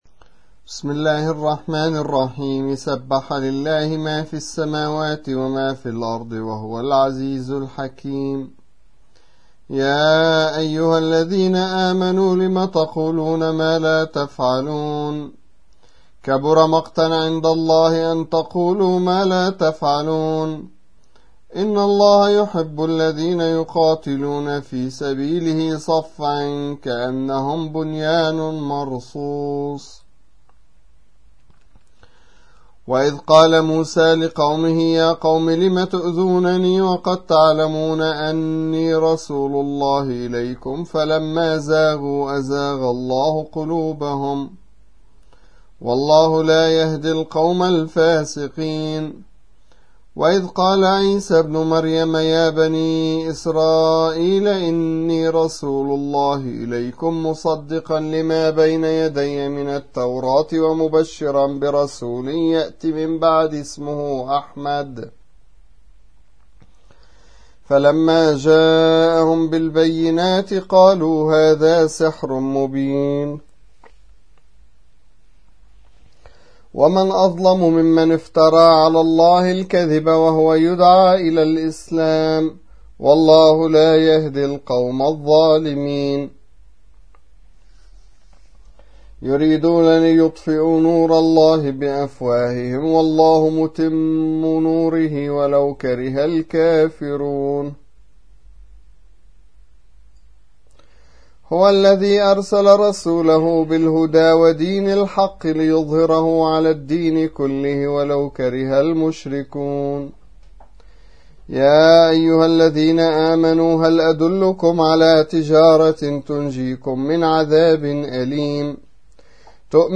61. سورة الصف / القارئ